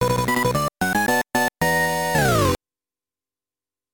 This music was recorded using the game's sound test.